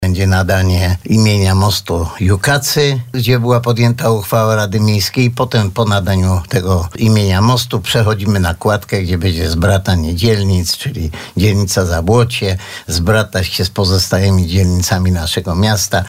Z kolei wczoraj na naszej antenie samorządowiec informował, że uroczystość rozpocznie od nadania kładce imienia zabłockich Jukaców.